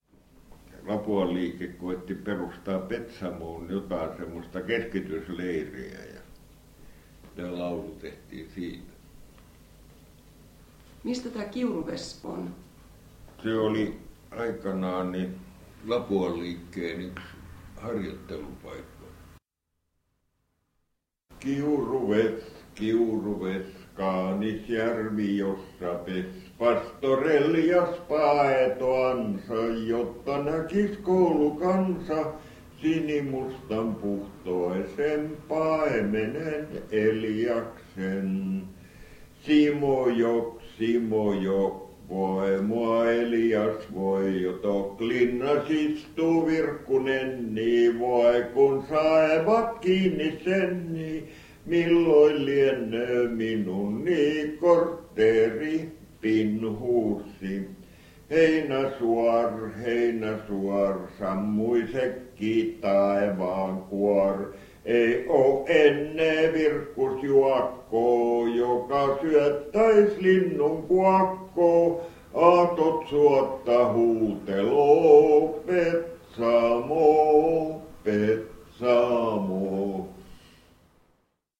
81 vuotiaana laulamia kisällilauluja